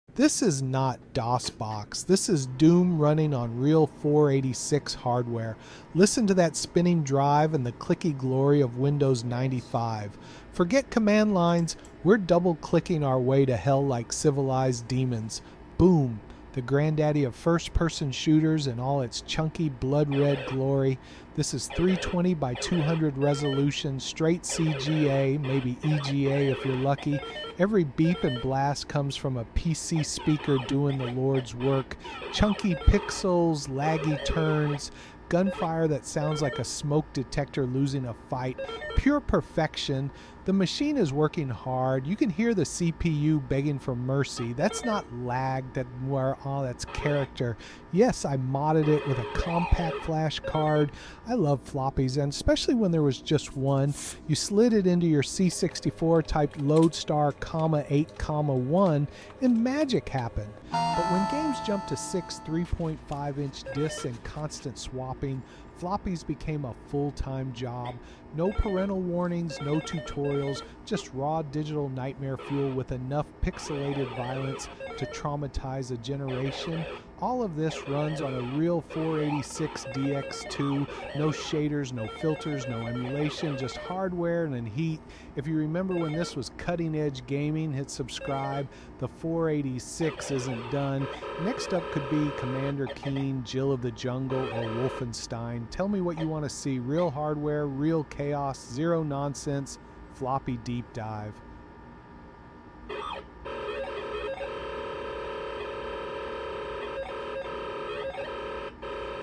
No emulators, no filters—pure DOS gaming nostalgia. We’re talking arrow-key movement, PC‑speaker gunfire, and that sweet 320×200 VGA chaos.